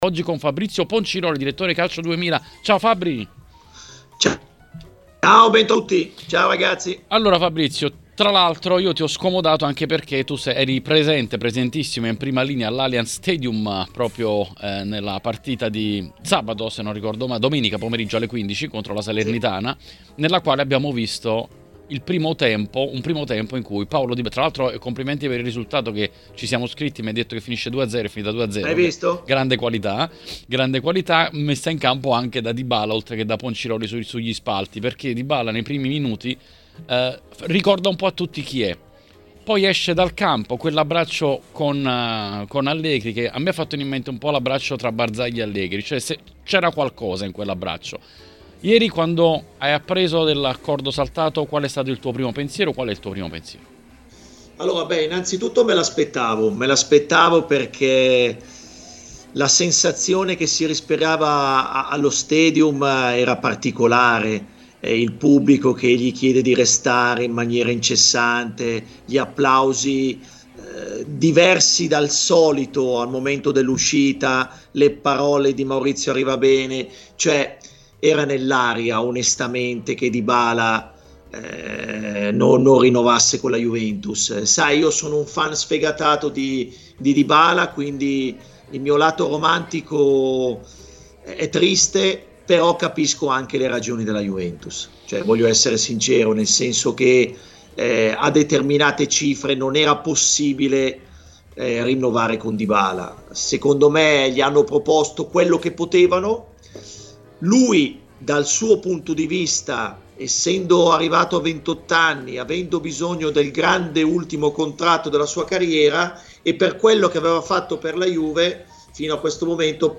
ospite dell’editoriale di TMW Radio.